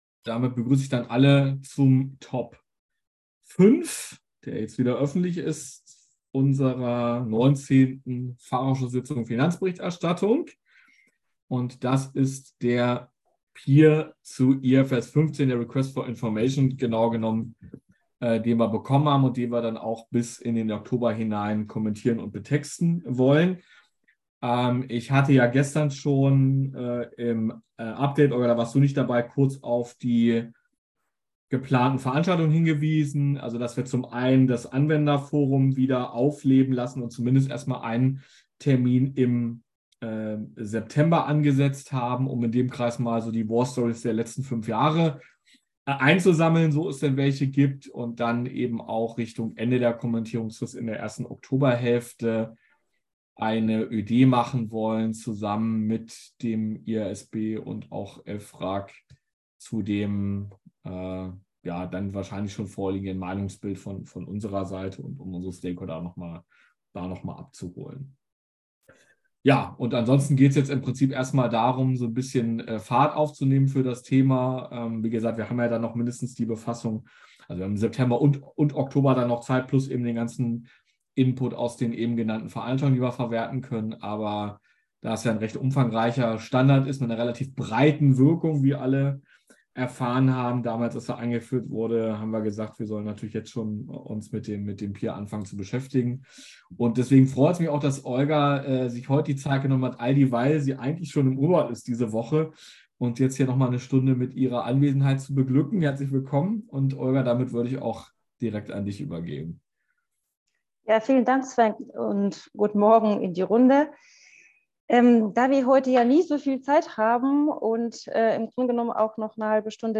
19. Sitzung FA Finanzberichterstattung • DRSC Website